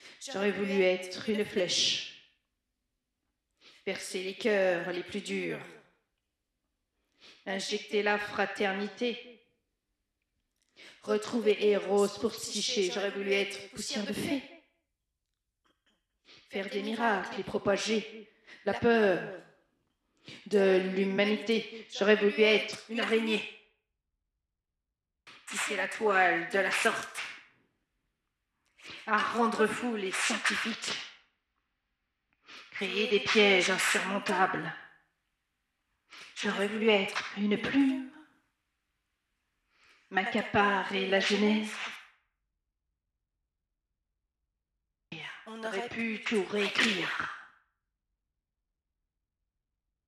paroles_133.wav